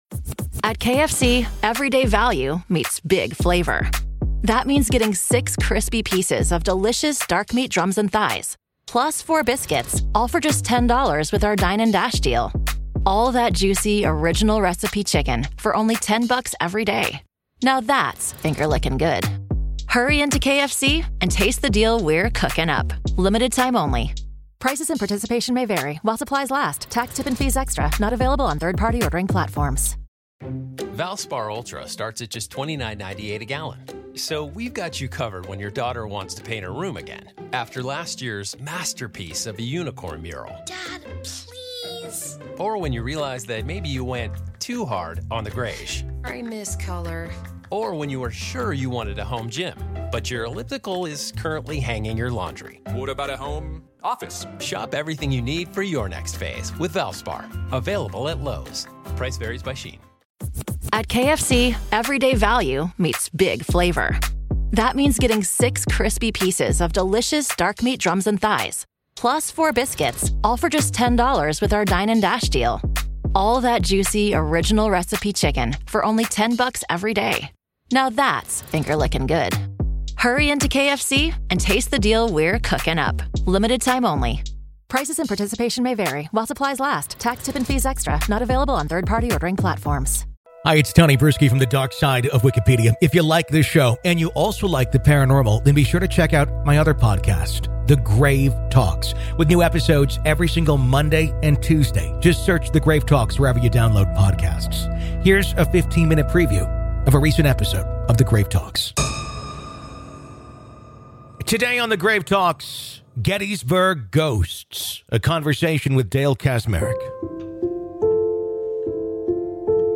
Gettysburg Ghosts | A Conversation